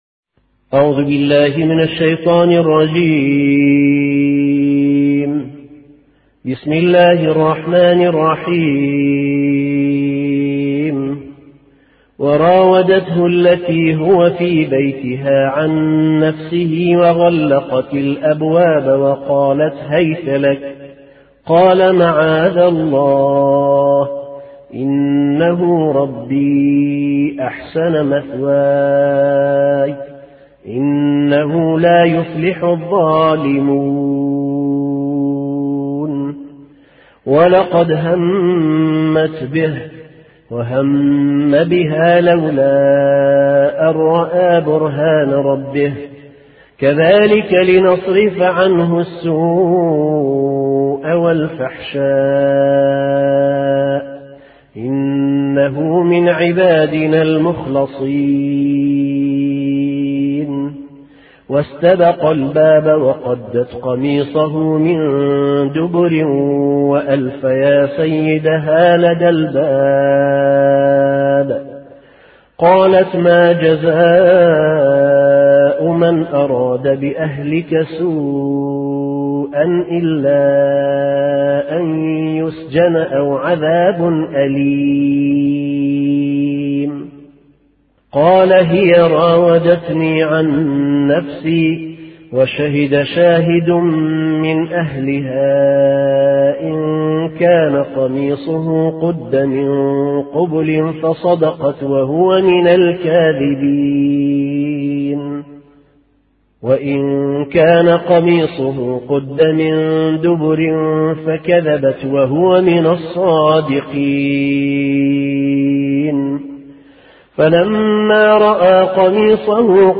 (5)تفسیر سوره یوسف توسط ماموستا کریکار به زبان کوردی